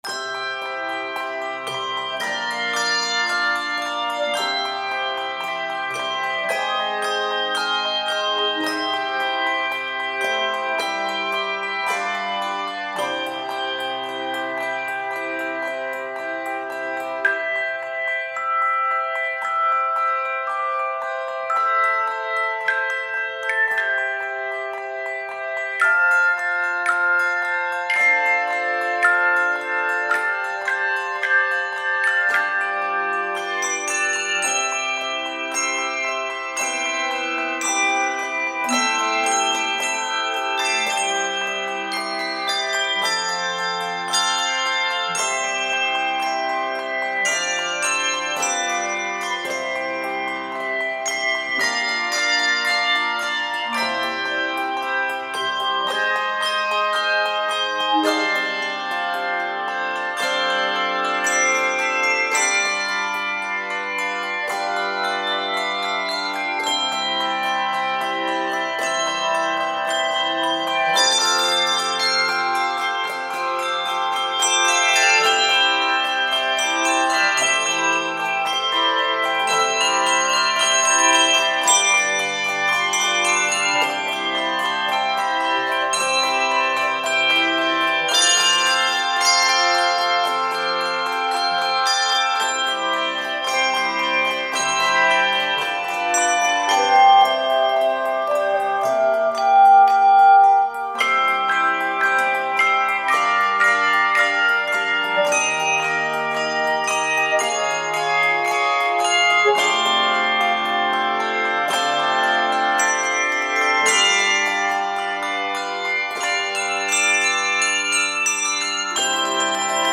Key of C Major. 71 measures.